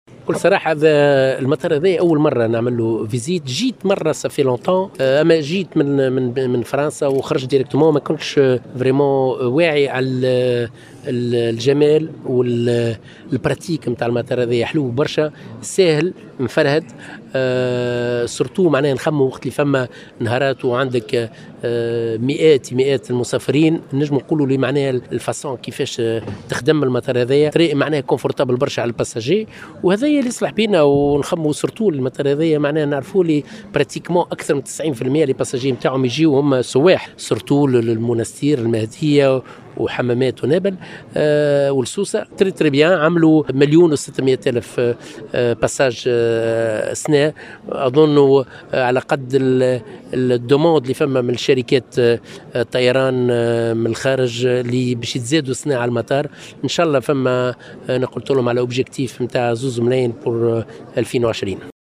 أكد وزير السياحة روني الطرابلسي في تصريح للجوهرة "اف ام" اليوم 4 ديسمبر 2019 أن مطار النفيضة الذي يحتفل اليوم بعيد ميلاده العاشر عملي ومريح للمسافرين بسبب هندسته وطريقة بنائه .